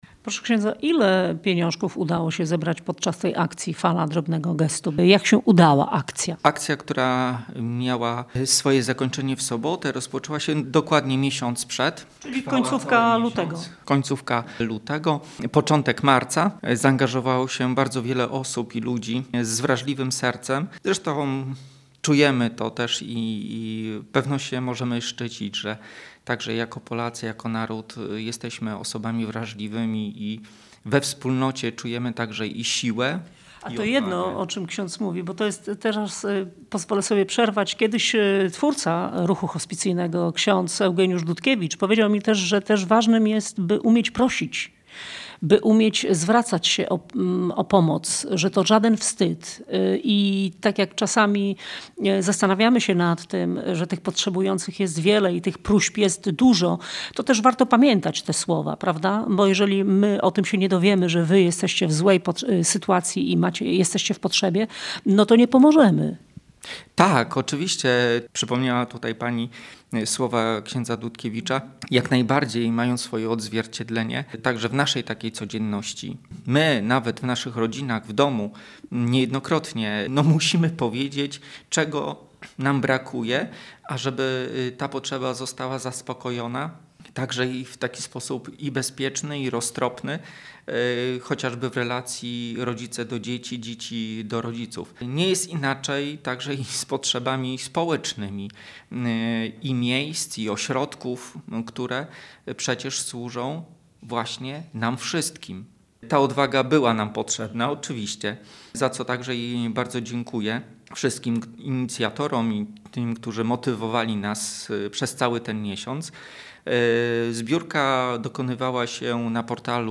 rozmawiała